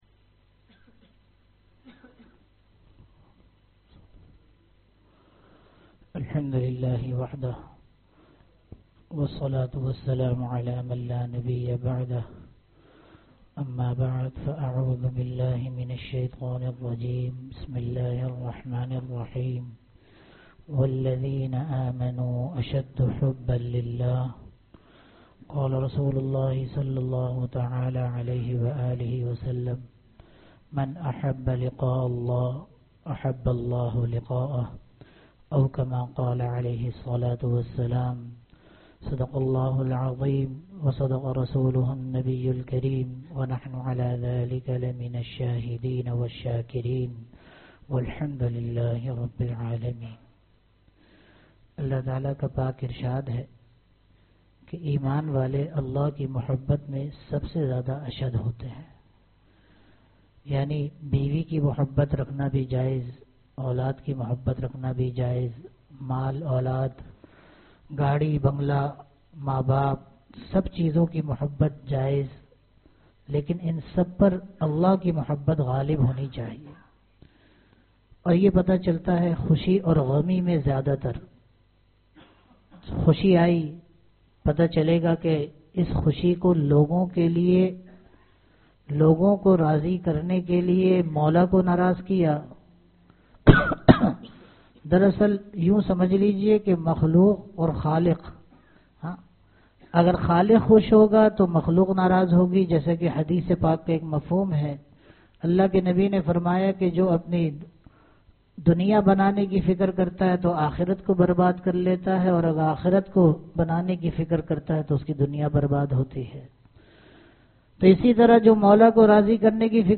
Bayanat
Bayanat (Jumma Aur Itwar) Allah tala ki muhubbat tamam cheezon par ashad honi chahie (jummah byan)